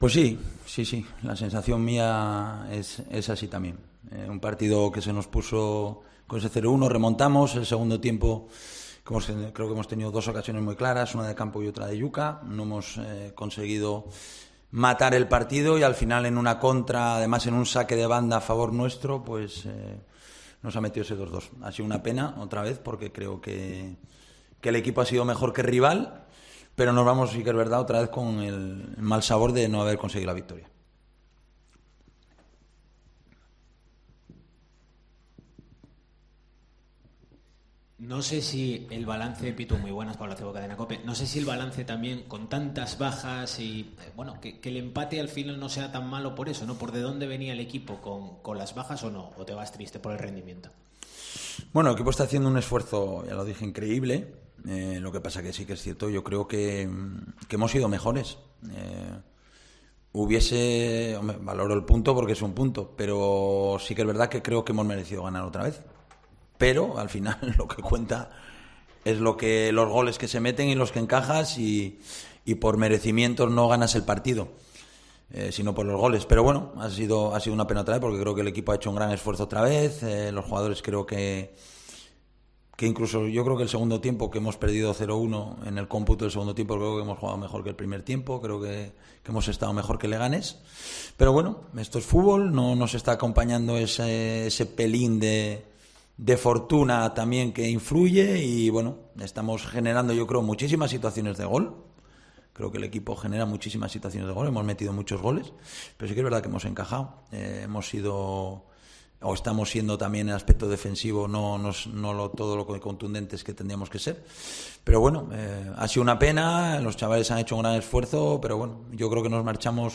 RUEDA DE PRENSA
Rueda de prensa Abelardo (post Leganés)